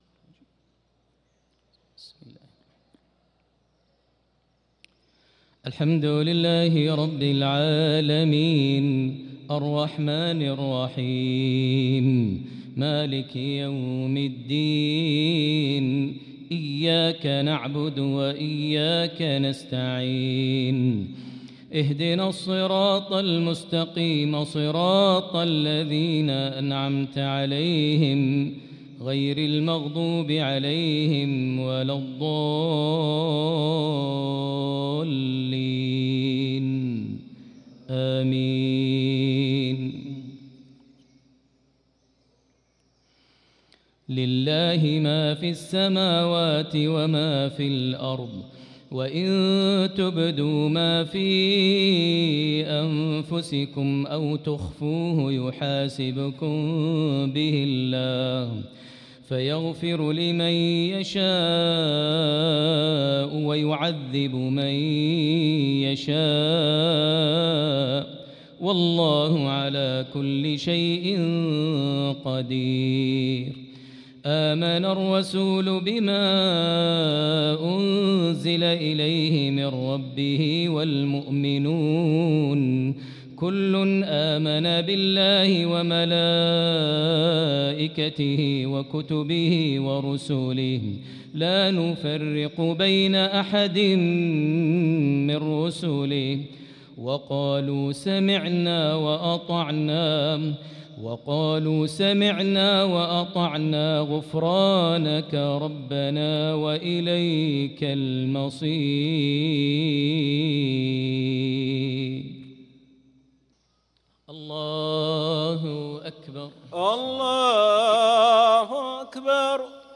صلاة المغرب للقارئ ماهر المعيقلي 4 شعبان 1445 هـ
تِلَاوَات الْحَرَمَيْن .